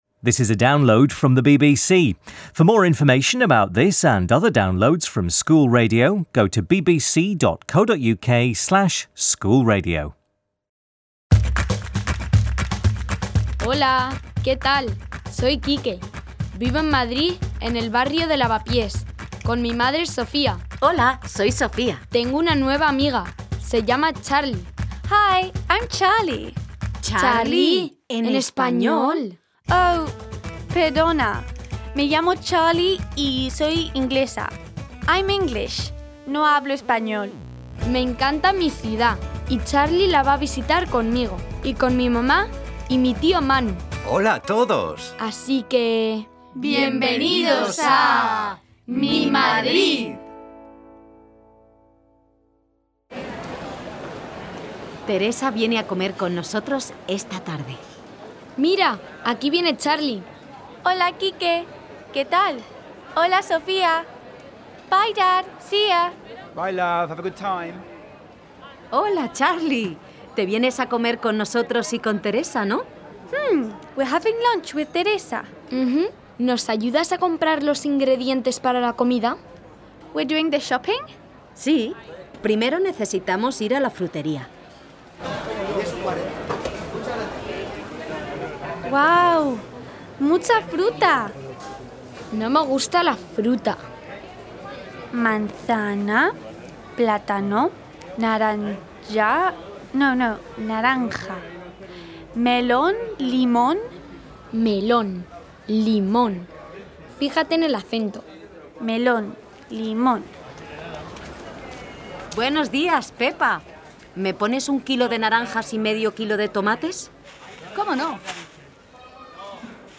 Charlie and Quique go to the market with Sofía to buy ingredients for a 'paella'. Teresa tells a story about Sergio - an easily-distracted boy who goes shopping for his father - and Uncle Manu sings about his favourite foods. Key vocabulary focuses on food and shopping and using the construction 'me gustaría' ('I like') as well as information about the Arabic origins of some Spanish words.